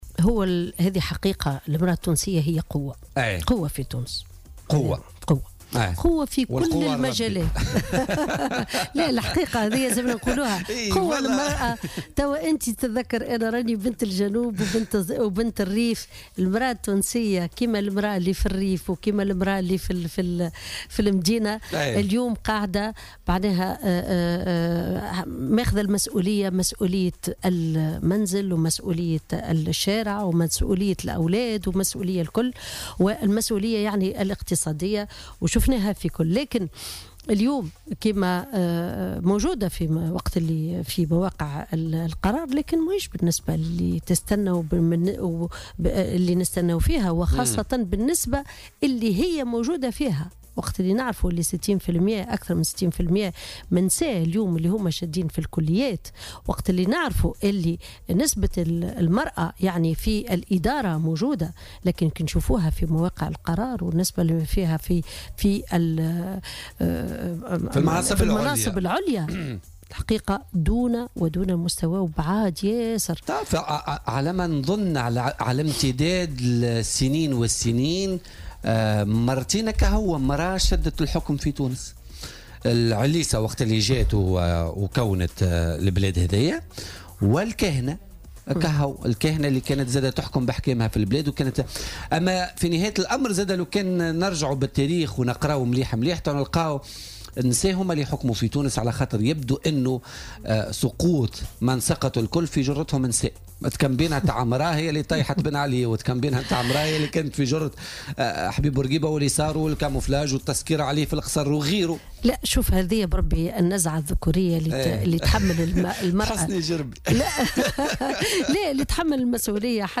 وانتقدت ضيفة "بوليتيكا" على "الجوهرة اف أم" في المقابل مكانة المرأة التونسية المتدنية في المراكز القيادية مشيرة إلى أن مرتبتها في مواقع القرار مازالت دون المستوى.